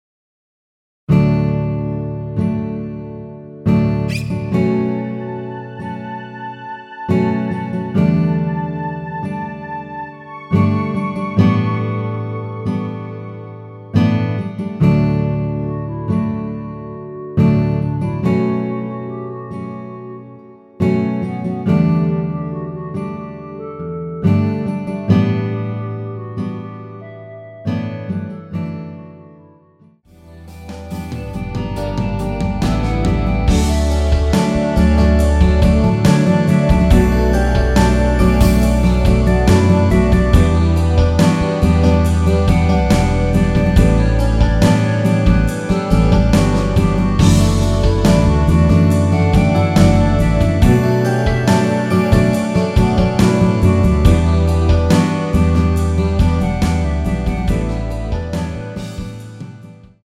원키에서(-1)내린 멜로디 포함된 MR 입니다.
멜로디 MR이라고 합니다.
앞부분30초, 뒷부분30초씩 편집해서 올려 드리고 있습니다.
중간에 음이 끈어지고 다시 나오는 이유는